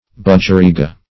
budgerigar \budg"er*i*gar\, budgereegah \budgereegah\,